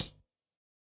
UHH_ElectroHatD_Hit-24.wav